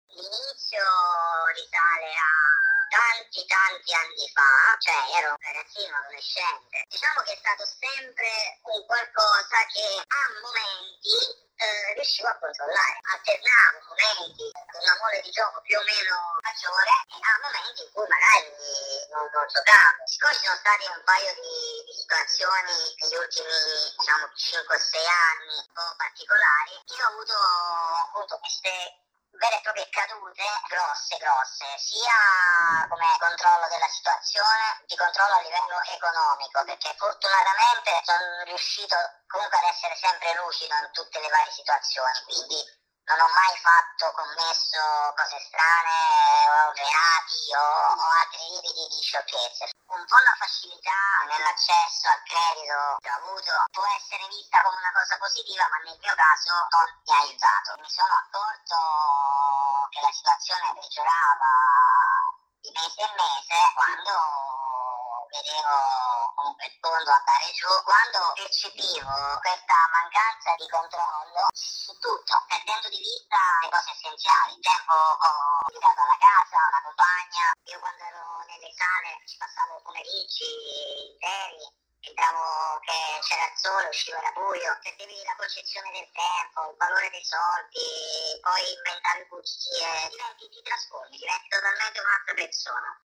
Abbiamo raccolto una testimonianza anonima, una storia di chi ha avuto un disturbo da gioco d’azzardo e che ha vissuto momenti difficili a causa di sale d’azzardo e slot machine.